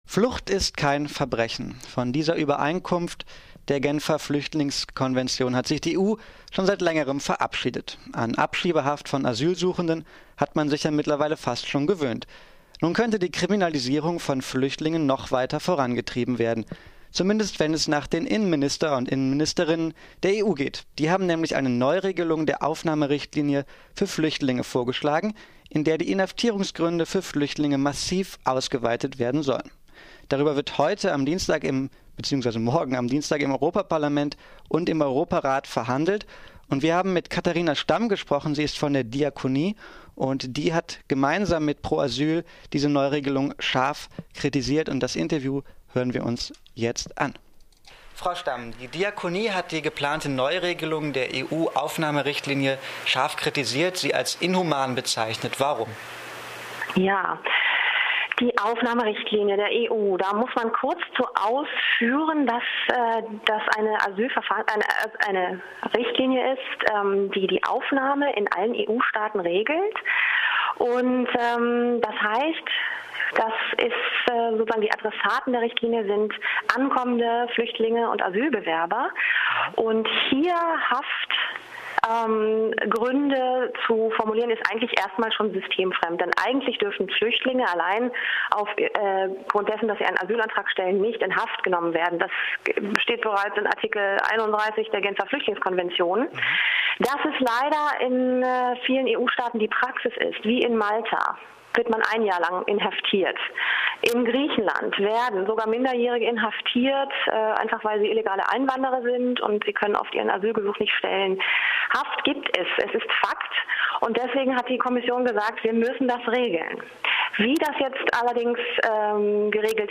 Neue EU-Richtlinie weitet Kriminalisierung von Flüchtlingen aus- Interview